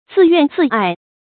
自怨自艾 zì yuàn zì yì 成语解释 原指自己悔恨；自己改正。现形容悔恨交加。
成语简拼 zyzy 成语注音 ㄗㄧˋ ㄧㄨㄢˋ ㄗㄧˋ ㄧˋ 常用程度 常用成语 感情色彩 贬义成语 成语用法 联合式；作谓语、状语、定语；含贬义 成语结构 联合式成语 产生年代 古代成语 成语正音 艾，不能读作“ài”。